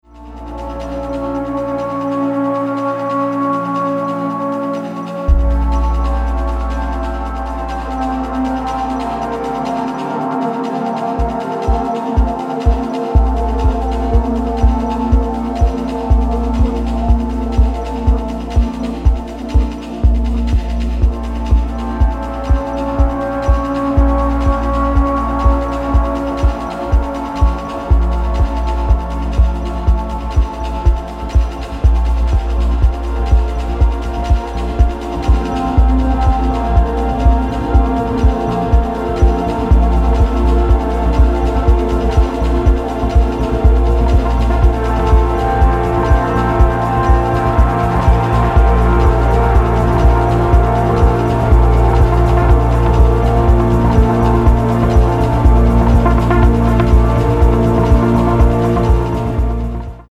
original motion picture score
entirely electronic and techno score